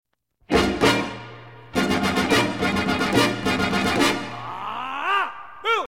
Interval